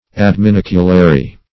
Adminiculary \Ad`mi*nic"u*la*ry\, a.